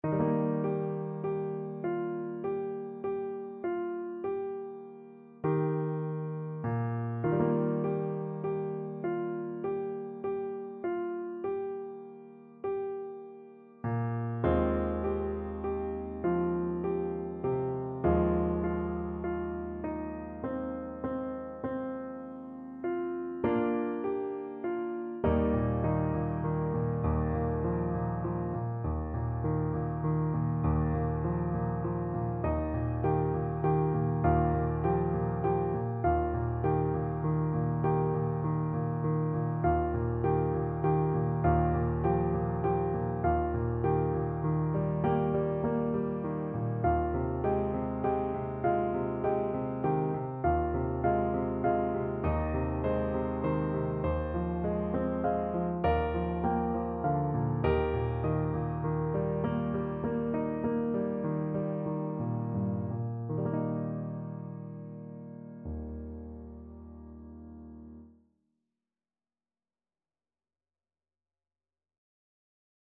No parts available for this pieces as it is for solo piano.
3/4 (View more 3/4 Music)
Eb major (Sounding Pitch) (View more Eb major Music for Piano )
Gently =c.100
Piano  (View more Intermediate Piano Music)
Classical (View more Classical Piano Music)